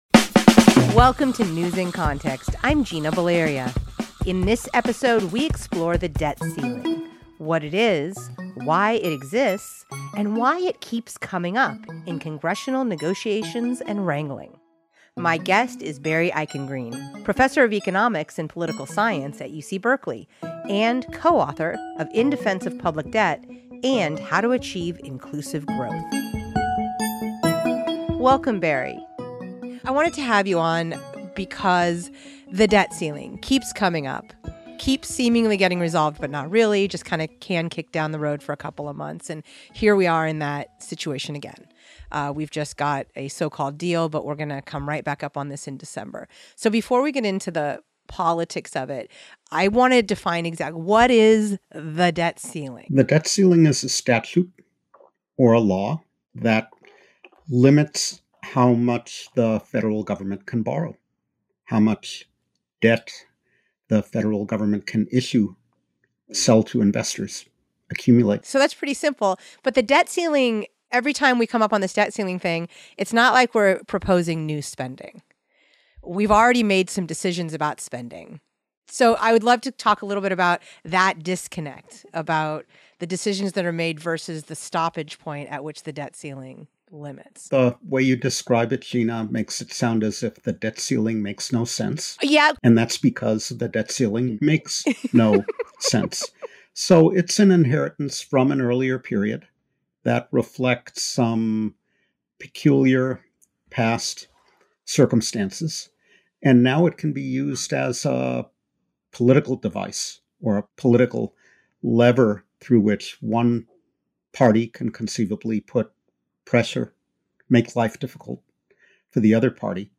My guest is Barry Eichengreen, professor of economics and political science at UC Berkeley, and co-author of In Defense of Public Debt, and How to Achieve Inclusive Growth.